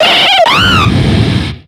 Cri de Simiabraz dans Pokémon X et Y.